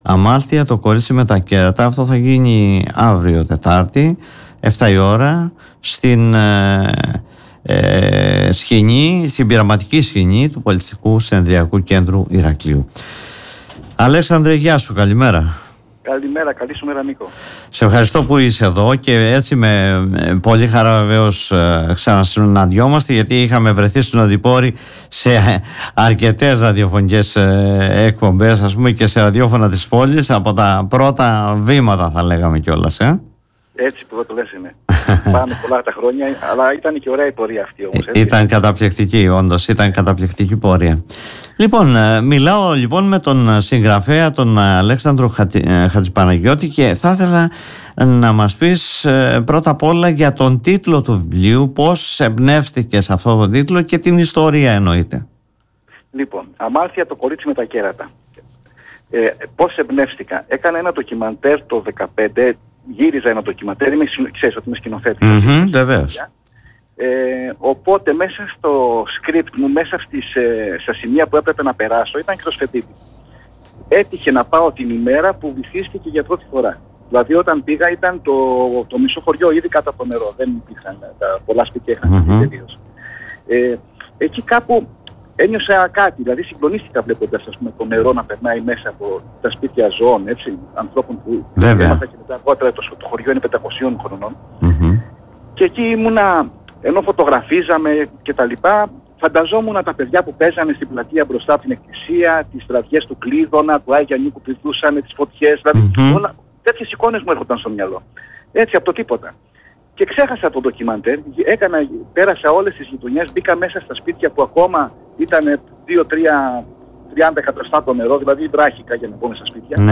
μίλησε στην εκπομπή “Δημοσίως”